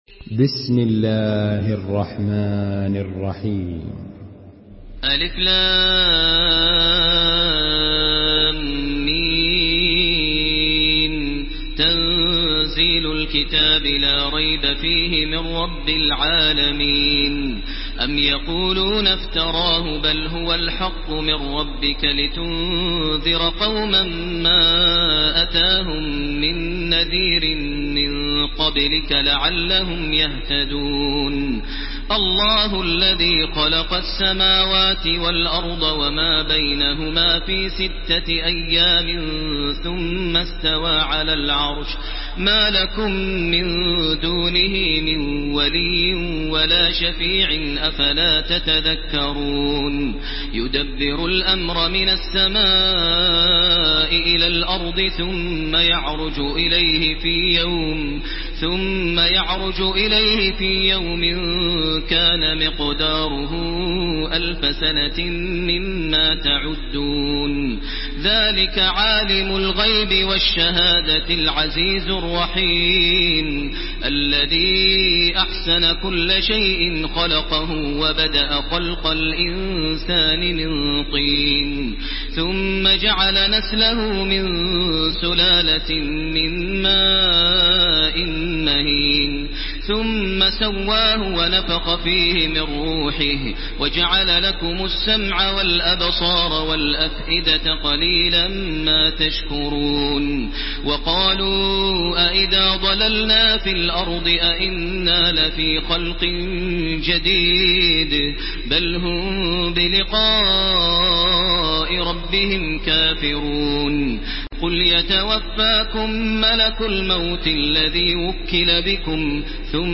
Makkah Taraweeh 1430
Murattal